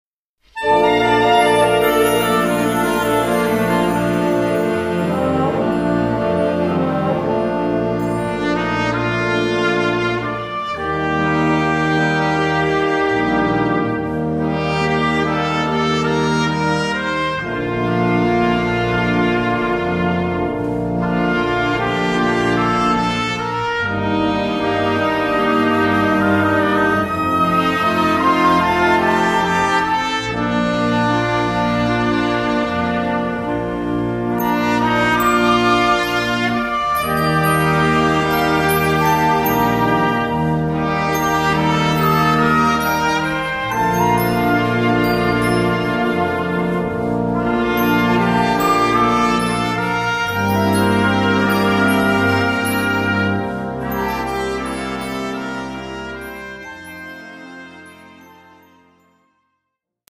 Gattung: Filmmusik
A4 Besetzung: Blasorchester Zu hören auf